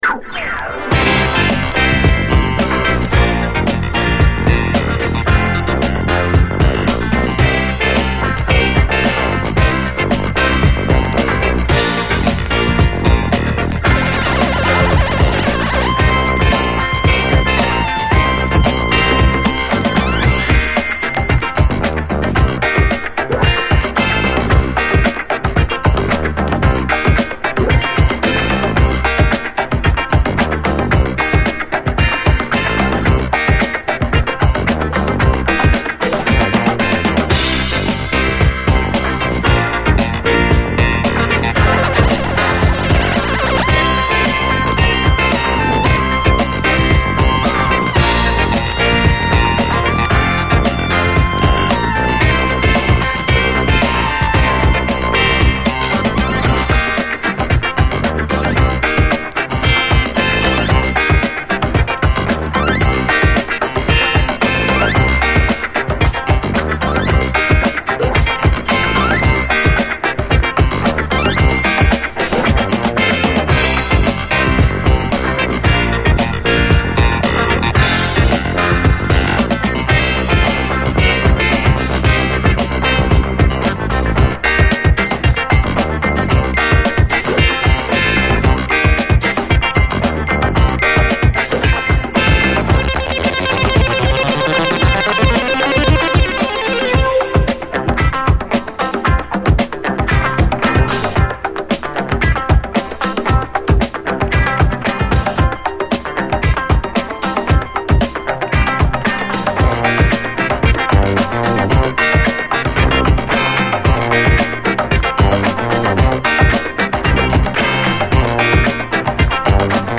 SOUL, 70's～ SOUL, 7INCH
ディスコ・ファンク・クラシック！